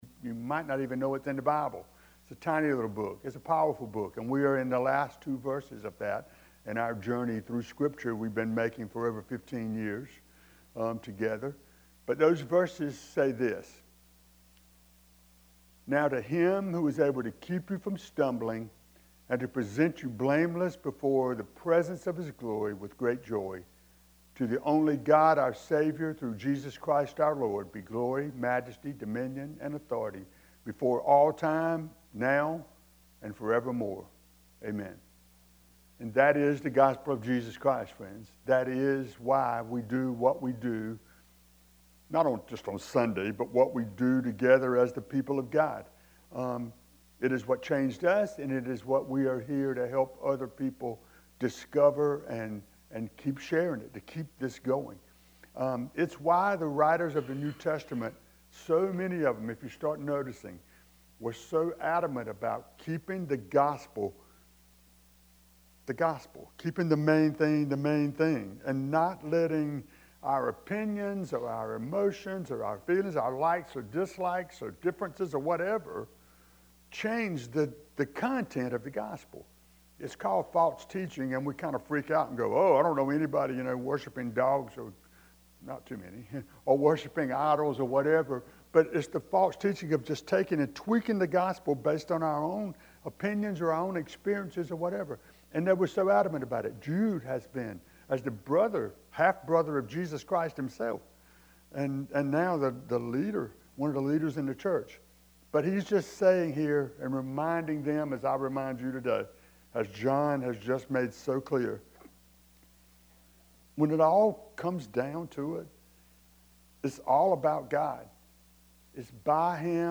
Roundtable Discussion on Depression & Suicide